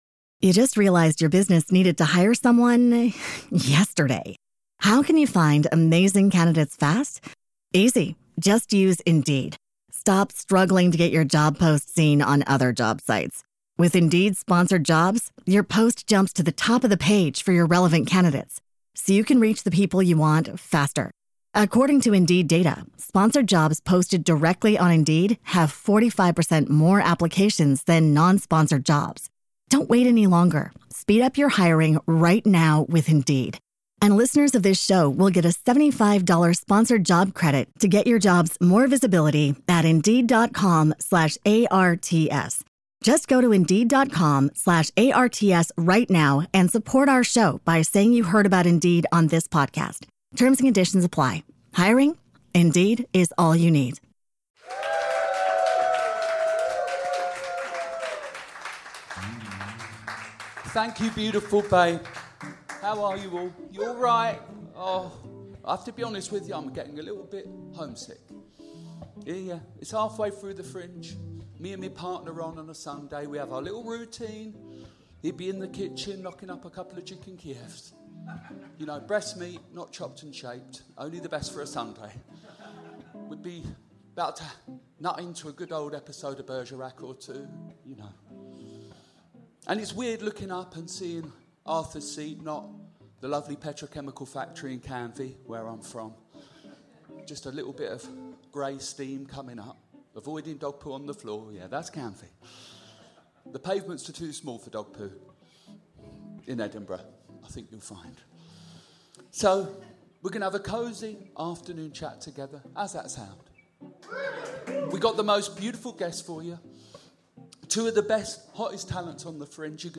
Live from the Edinburgh Fringe Festival
You can come and see episodes recorded live from the 5th-17th August in Edinburgh!
This episode contains sexual references and strong language.